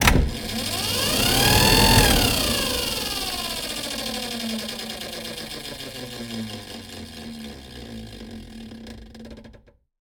transporthangar.ogg